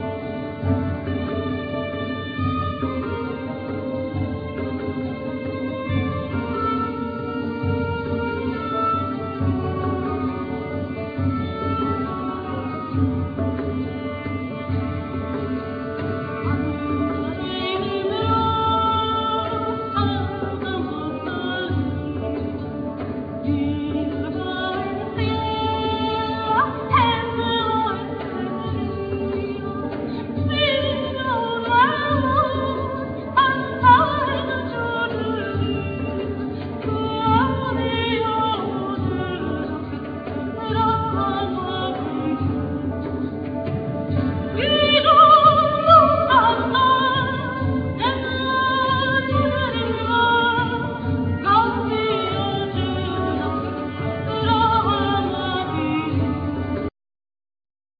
Vocal,Portative organ
Percussions
Bombarde,Shofar,Recorder,Santur,Vocal
Lute
Ud
Didjeridu,Shakuhachi,Jew's harp
Alto fiddle